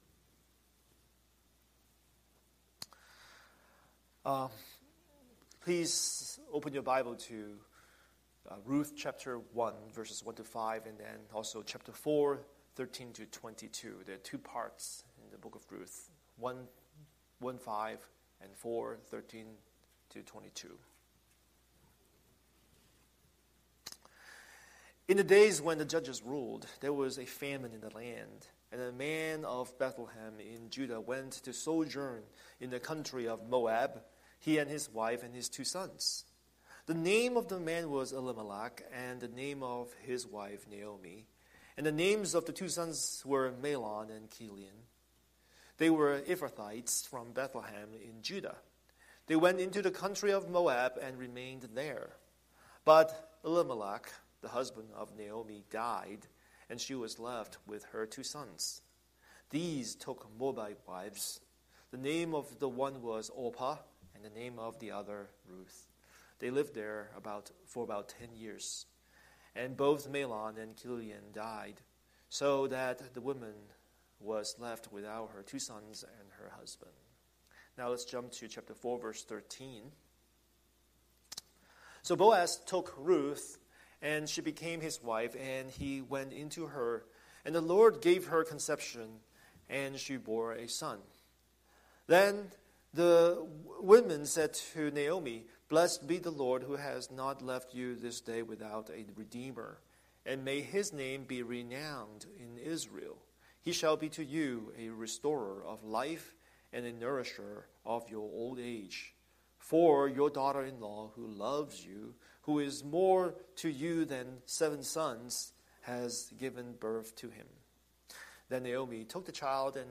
Scripture: Ruth 1:1-5, 4:13-22 Series: Sunday Sermon